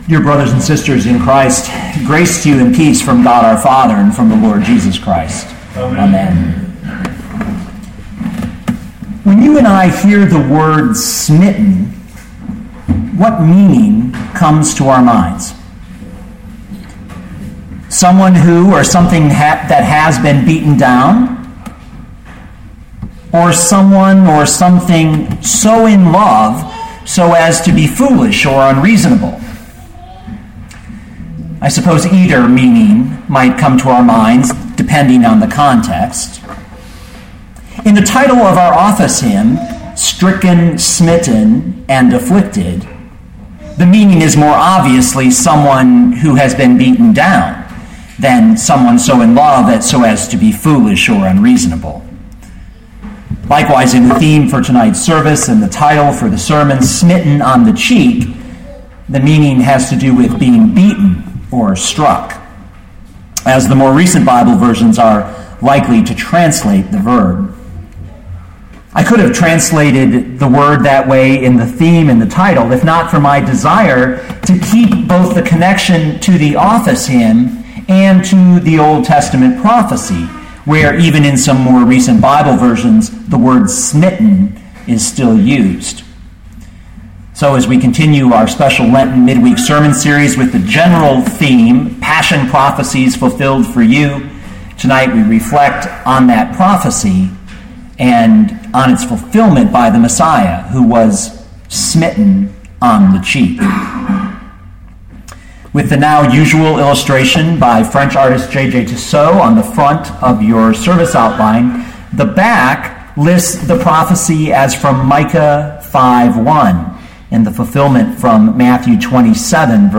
Matthew 27:27-31 Listen to the sermon with the player below, or, download the audio.